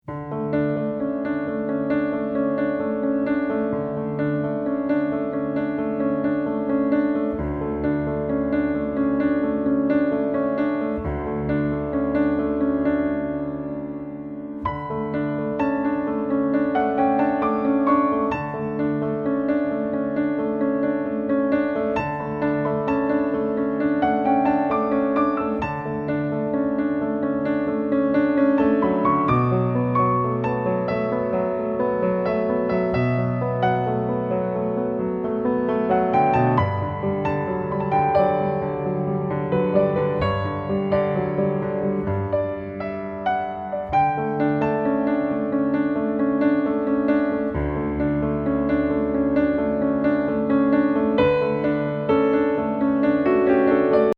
Level : Easy | Key : D | Individual PDF : $3.99